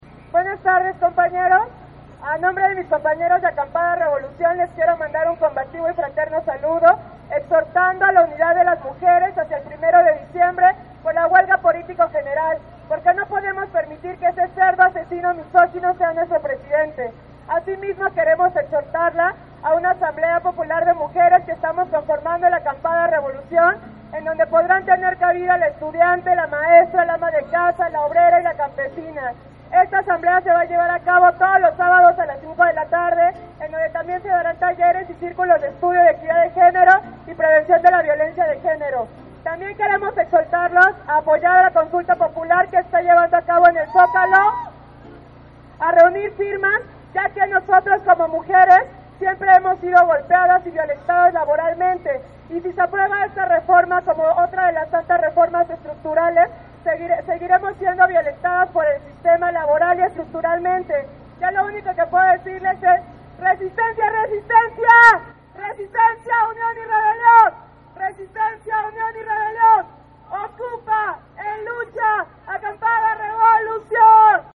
El pasado 25 de noviembre se realizó una marcha por el 25 de noviembre "día internacional contra la violencia a la Mujer"que partió del monumento a la Madre hacia el Palacio de Bellas Artes, la cual inició a medio día, esta fue convocada por colectivos feministas, como Pan y Rosas, Mujeres y la Sexta, entre otras; apróximadamente a las 2 de la tarde arribaron a la av. Juarez donde se llevó acabo la actividad político-cultural.